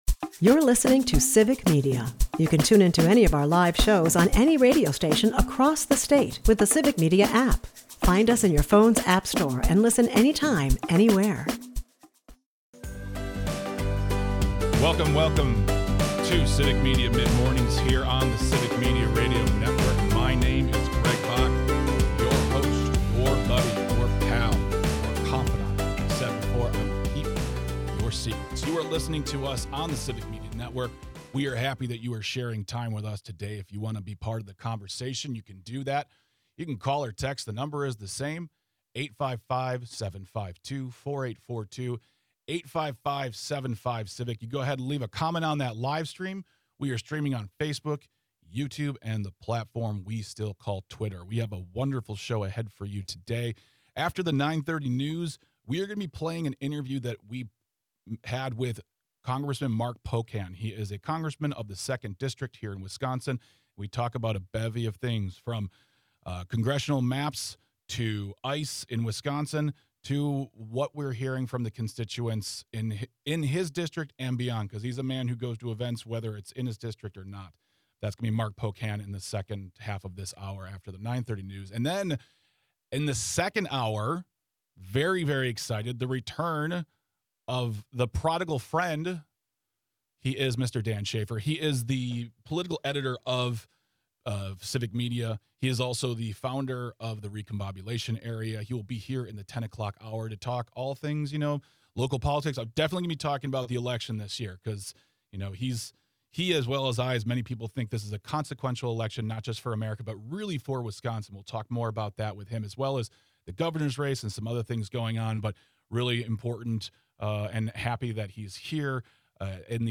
The episode really dug into the grit of Wisconsin politics, featuring a conversation with Congressman Mark Pocan about the high stakes of redrawing the state’s congressional maps and the "unsettling" arrival of ICE operations.